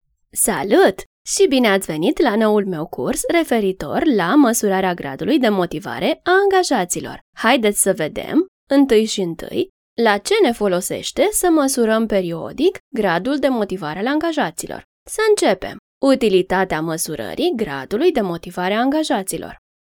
Zuverlässig, Freundlich, Warm, Sanft, Corporate
Audioguide
She can be professional in corporate e-learning but also nice and soft in stories for children.